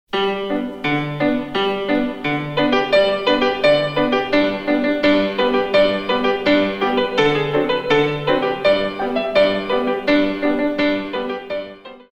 32 Counts